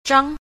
a. 張 – zhāng – trương